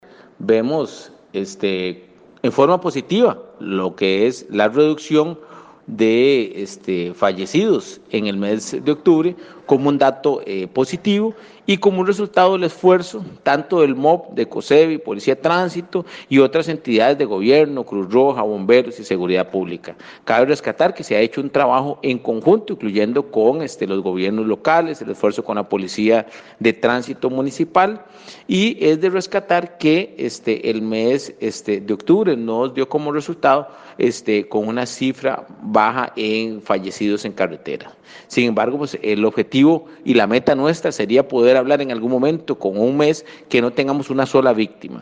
El director de la Policía de Tránsito, German Marín, manifestó que ven de forma positiva la reducción en el número de fallecidos en carretera.